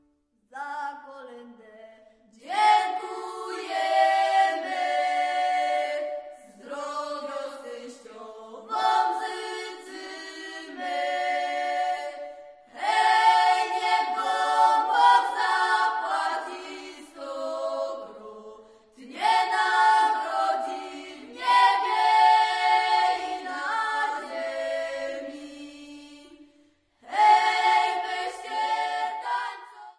Double Bass